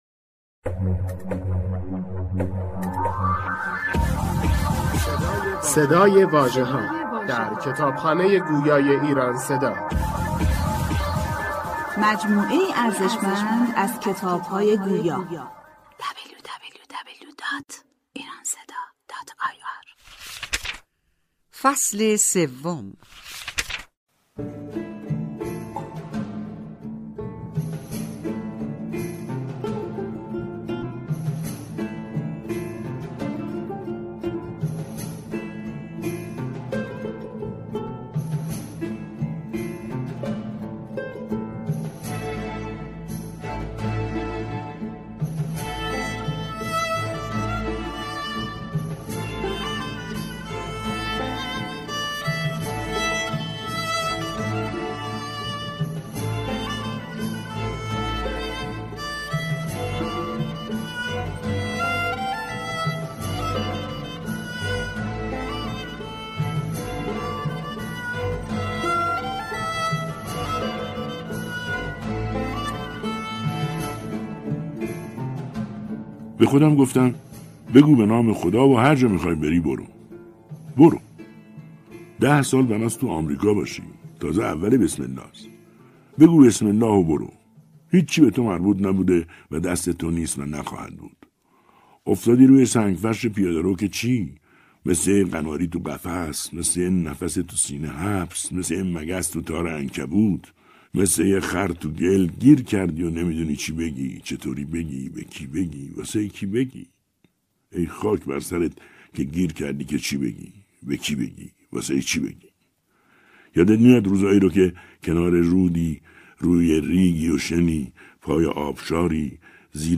کتاب صوتی ده سال هوملسی امریکا نوشته سید محمود گلابدره ای فصل سوم